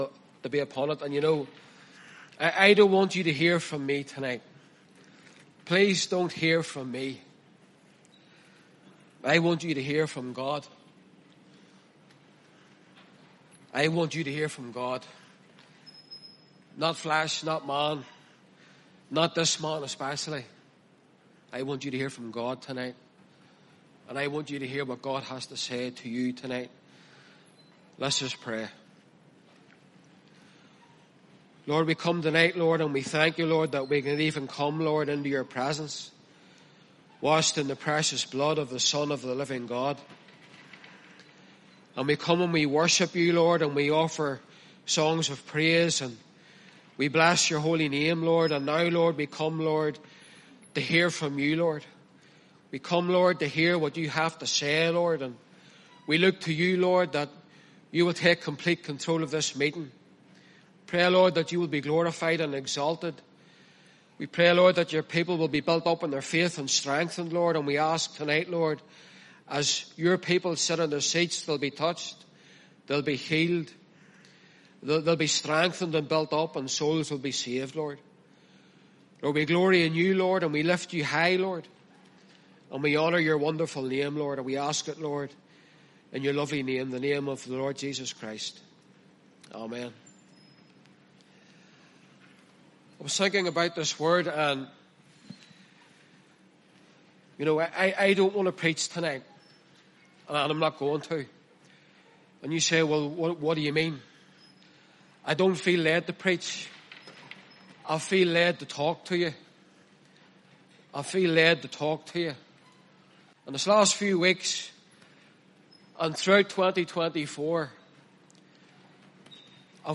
Sermons and Bible Teachings